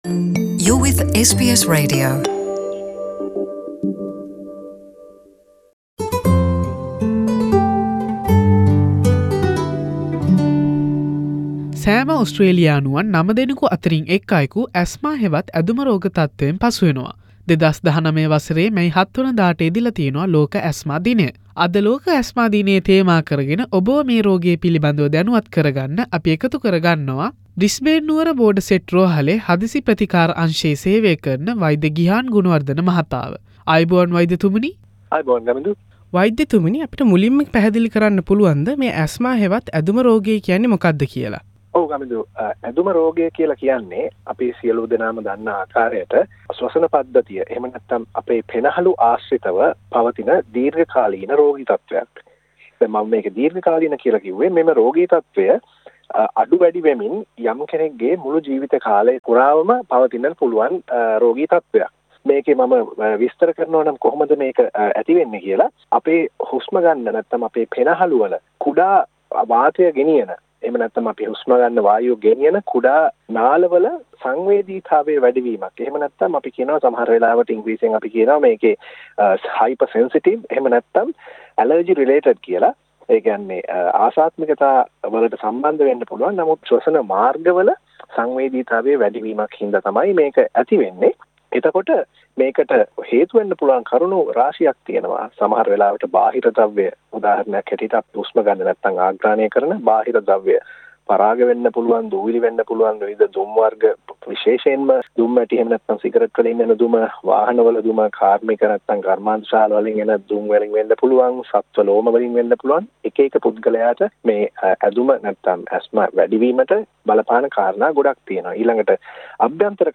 සාකච්චාව